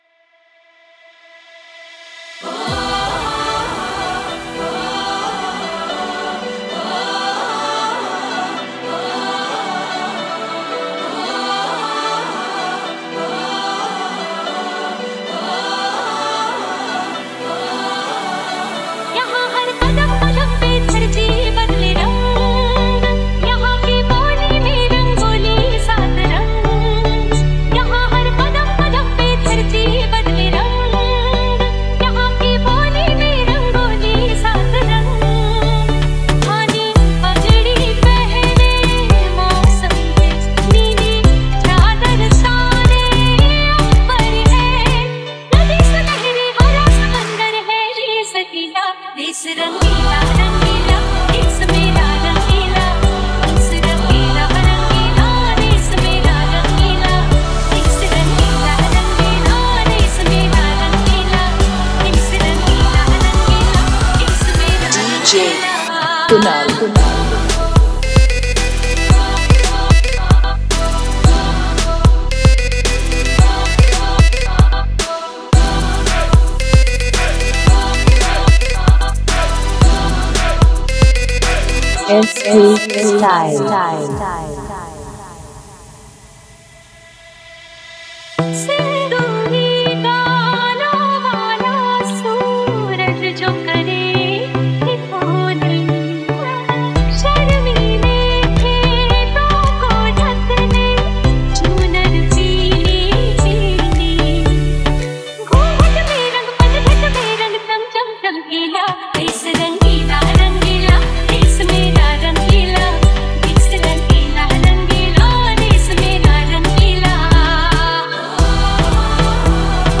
Desh Bhakti Dj Remix Song